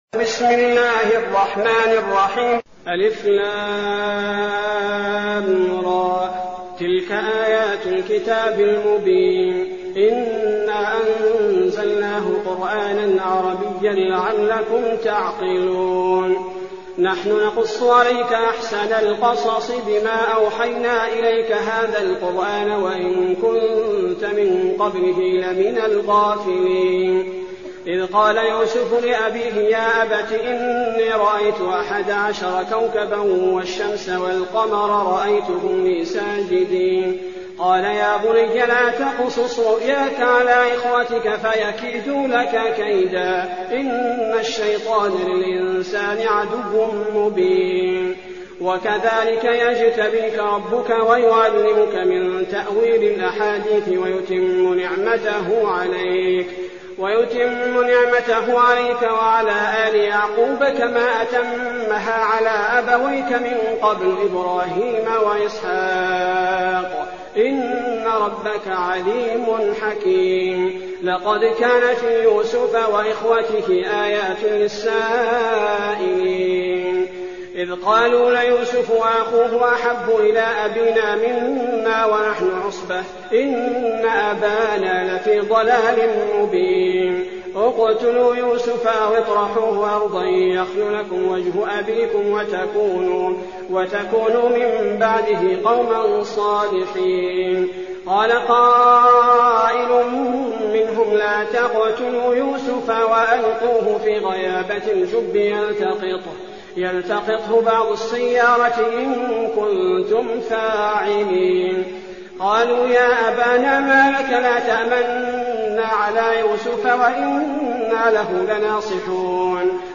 المكان: المسجد النبوي الشيخ: فضيلة الشيخ عبدالباري الثبيتي فضيلة الشيخ عبدالباري الثبيتي يوسف